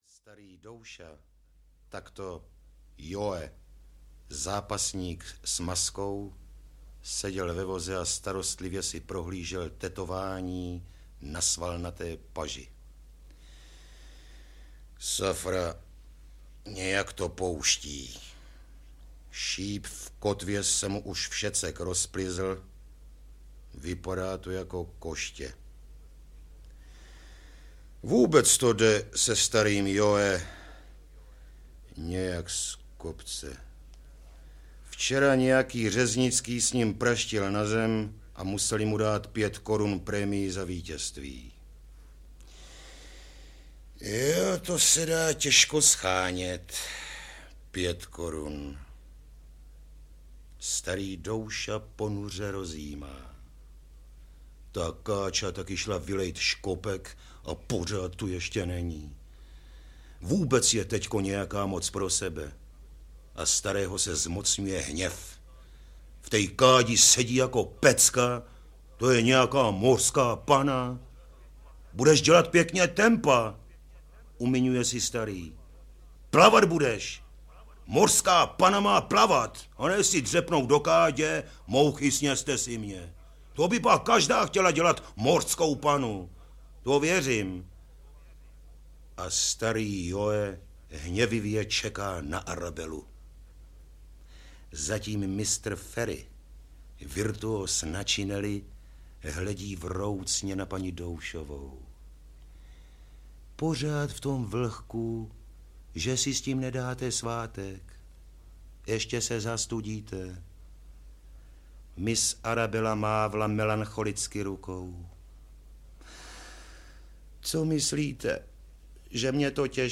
Ukázka z knihy
Bylo jen otázkou času, kdy po titulech s archivními popovými písničkami v serii "Supraphon hraje..." navážeme s obdobnou formou zpřístupňování archivního mluveného slova našim milým zákazníkům a posluchačům.